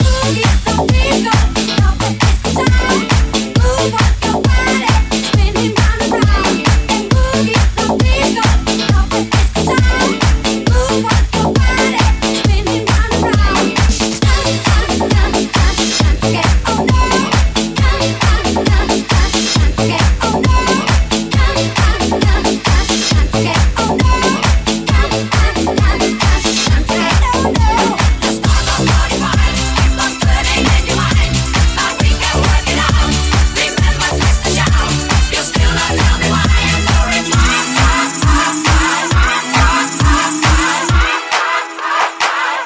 hits remixed
Genere: club, dance, edm, electro, house, successi, remix